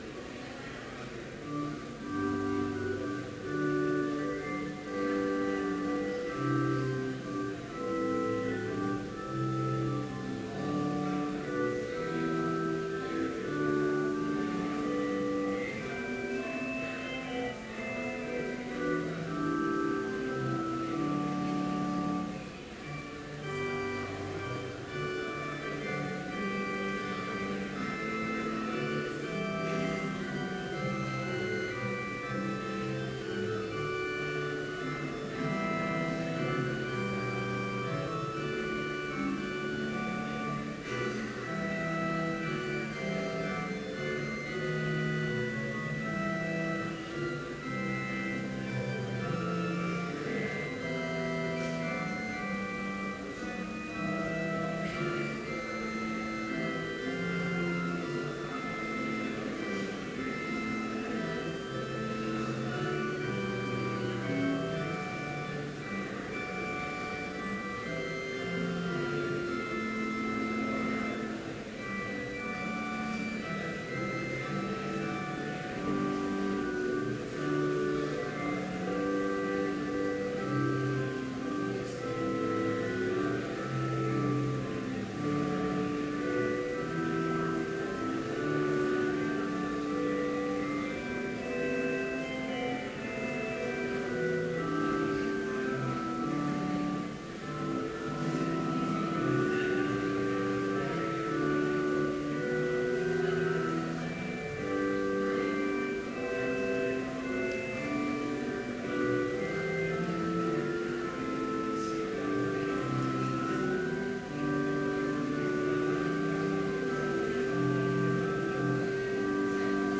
Chapel worship service held on December 10, 2014, BLC Trinity Chapel, Mankato, Minnesota, (audio available)
Complete service audio for Chapel - December 10, 2014
Order of Service Prelude Hymn 100, vv. 1, 2 & 4, The Bridegroom Soon Will Call Us Reading: Luke 17:20-21 Homily Prayer Hymn 100, vv. 5 & 6, In yonder home… Benediction Postlude